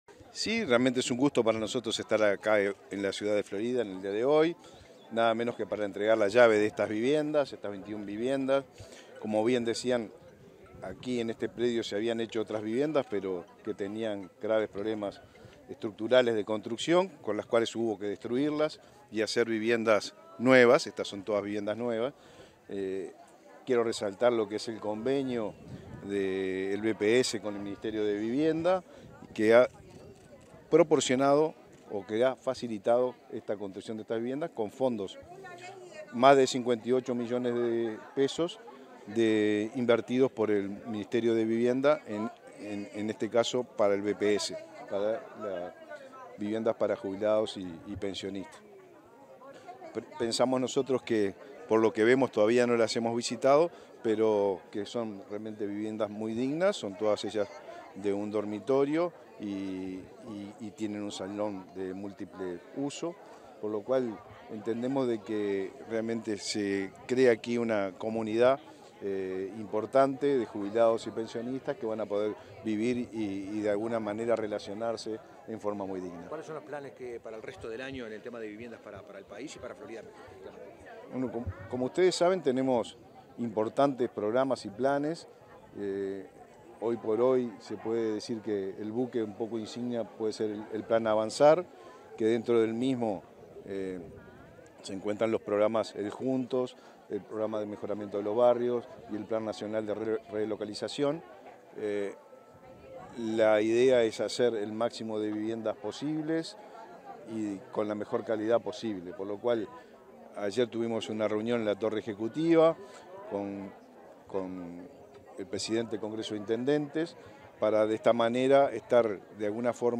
Declaraciones del ministro de Vivienda y Ordenamiento Territorial, Raúl Lozano
Declaraciones del ministro de Vivienda y Ordenamiento Territorial, Raúl Lozano 08/06/2023 Compartir Facebook X Copiar enlace WhatsApp LinkedIn Tras participar en la entrega de soluciones habitacionales a jubilados y pensionistas en Florida, este 8 de junio, el ministro de Vivienda y Ordenamiento Territorial, Raúl Lozano, realizó declaraciones a la prensa.